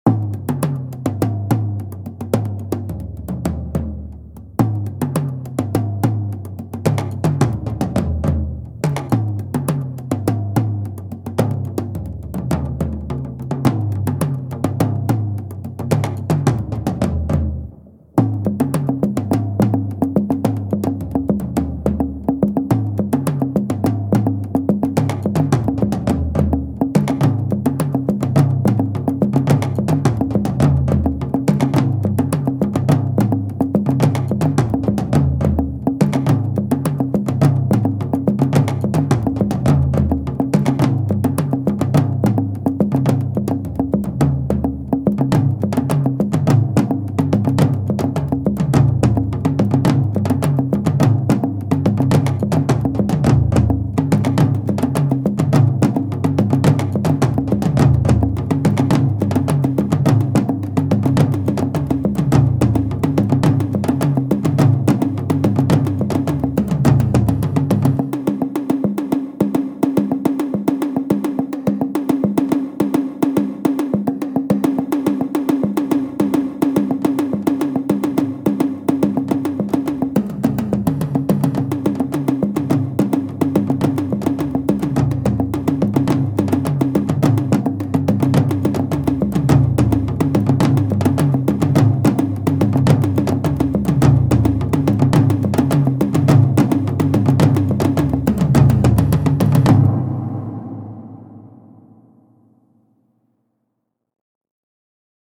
Piece featuring an oriental orchestra